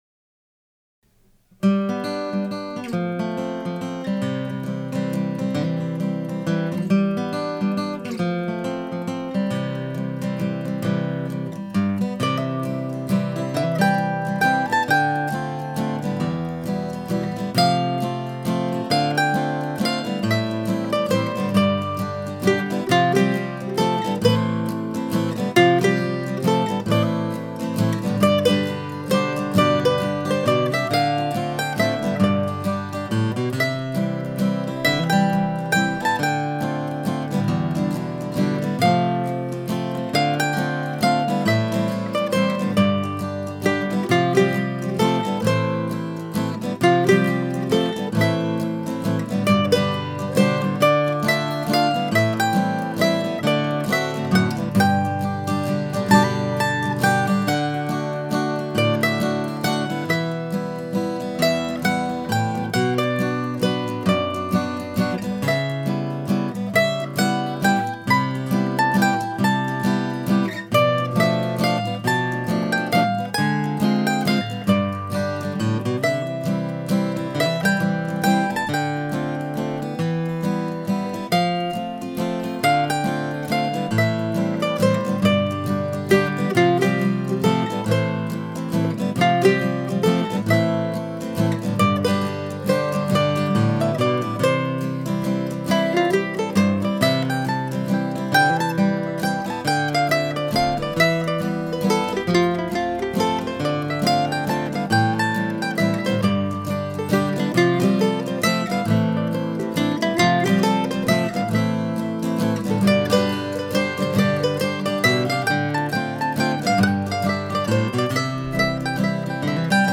Waltz.
The tune offers a good chance to practice your half-diminished chords.